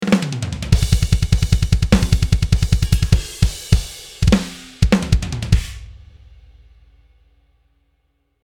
Описание: Ударная установка
Unprocessed. Сырые записи, позволяющие формировать и создавать собственные уникальные барабанные тембры
Unprocessed